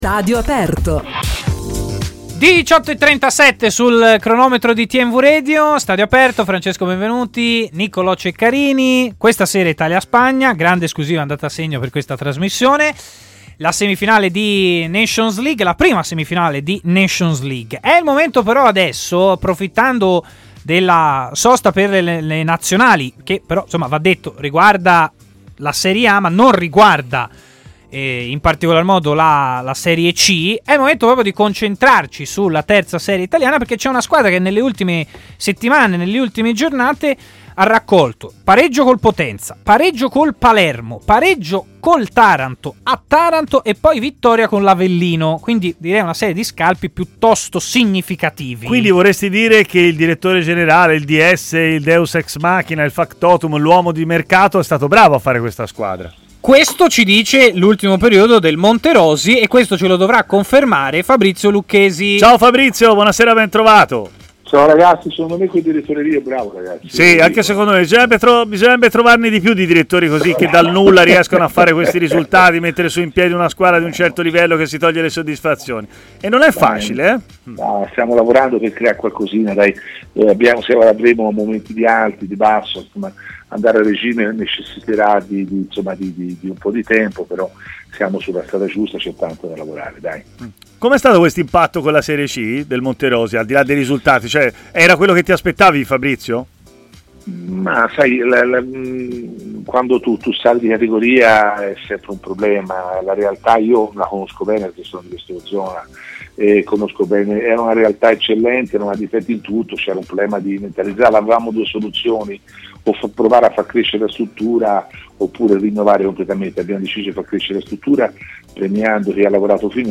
è intervenuto in diretta a Stadio Aperto